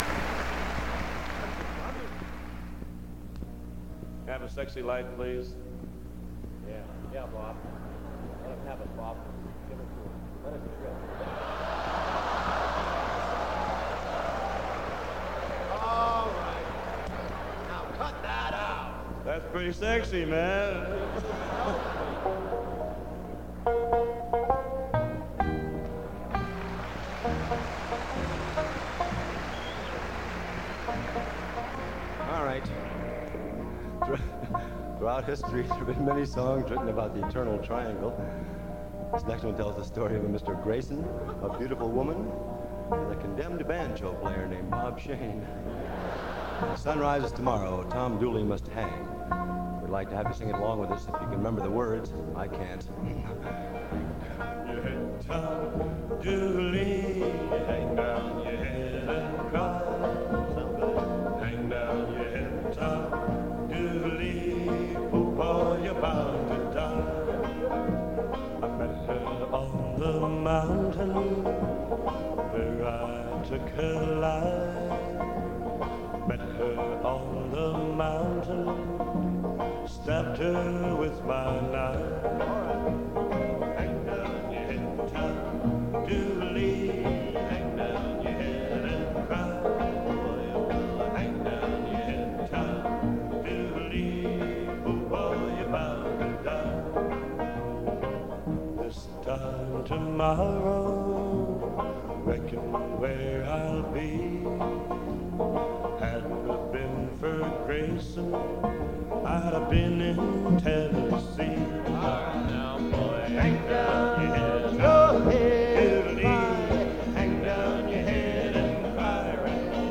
This is a bootleg of a concert from sometime in 1963.